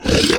spit4.wav